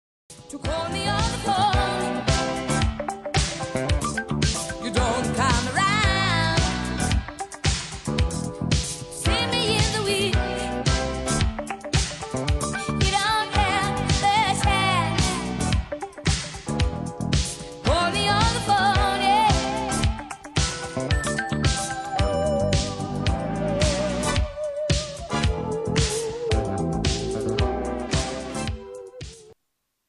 به سبک بابلگام